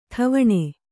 ♪ ṭhavaṇe